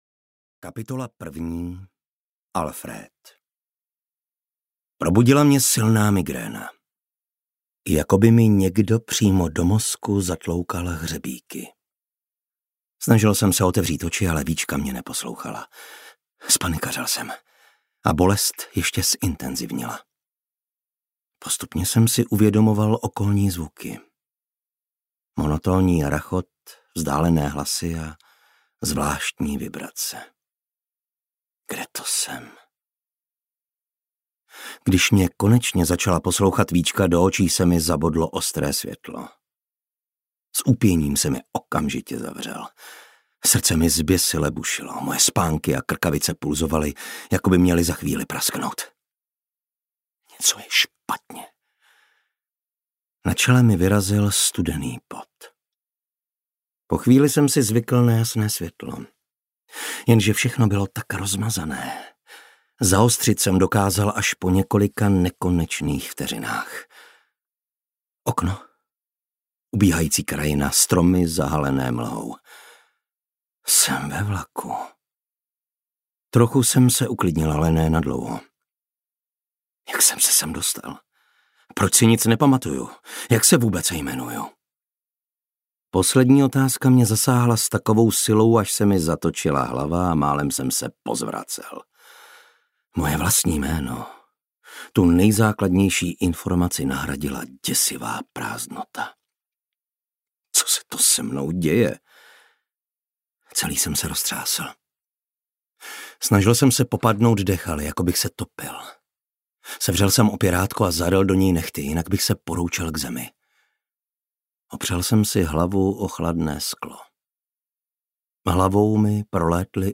Duše audiokniha
Ukázka z knihy